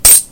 household sounds » 7 quarters fall on wooden floor
Nice decay.
标签： change quarter currency coins money coin
声道立体声